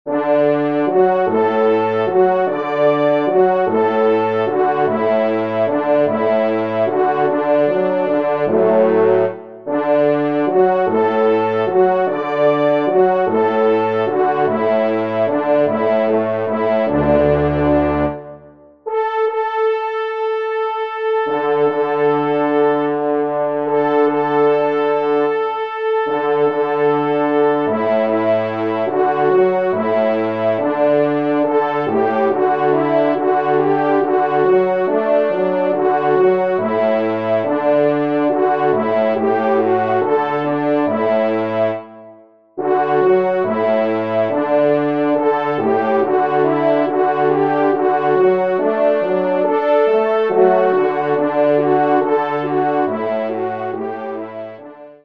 3e Trompe